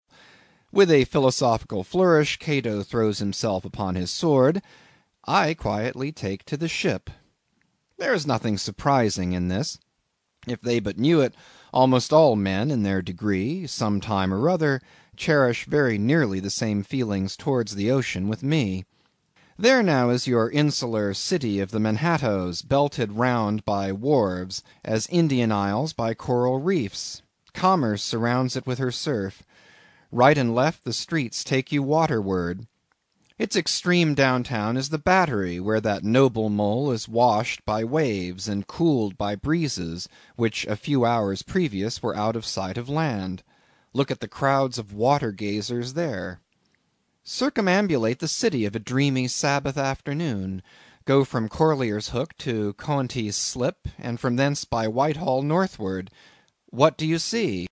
英语听书《白鲸记》第167期 听力文件下载—在线英语听力室